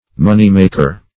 Money-maker \Mon"ey-mak`er\, n.